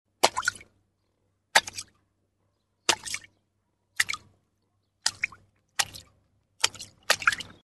Звуки лужи
Звук всплеска от шлепка ладонью по луже